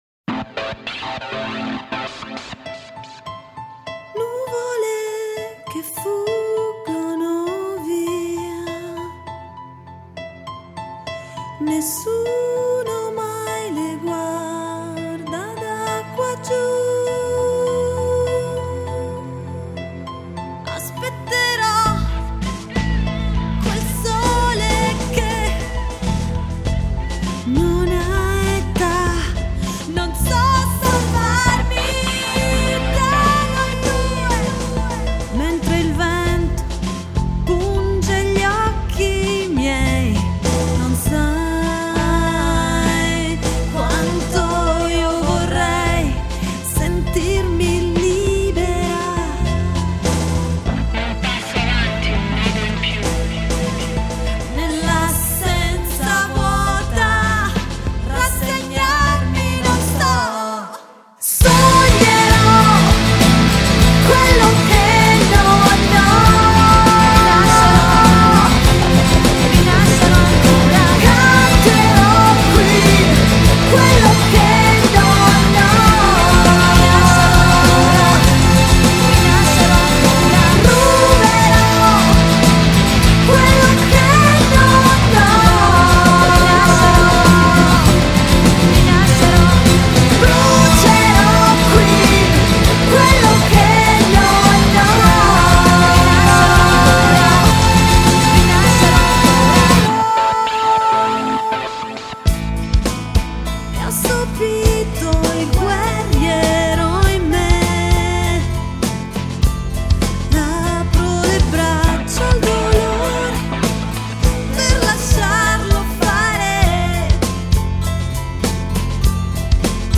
Vocals
Guitars, Bass, Keyboard, Programming
Drums